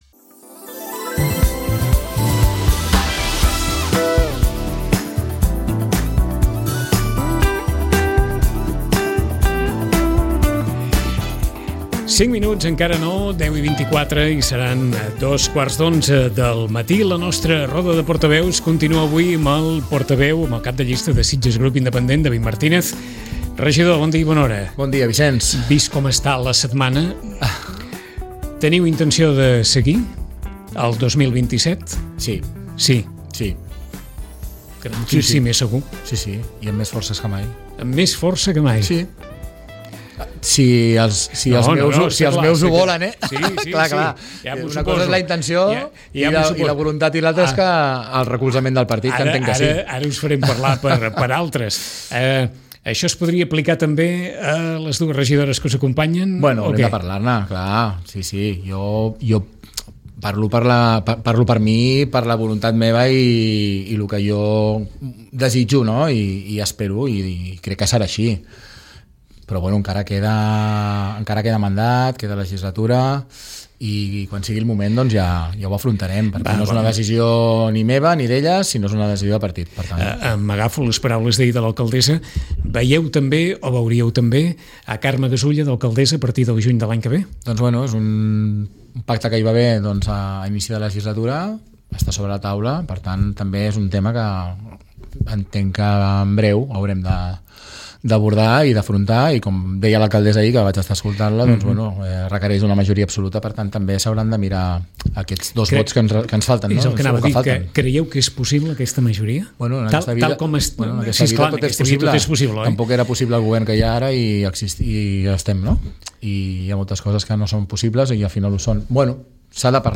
Amb el regidor també hem conversat sobre la presència de rodamons a l’espai públic i la gestió de la venda ambulant.